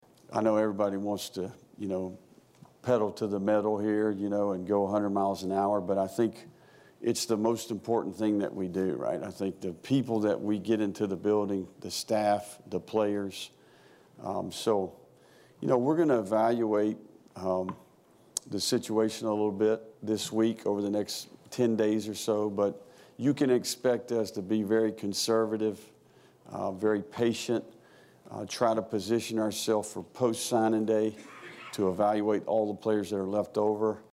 The quick version given at the press conference goes as follows: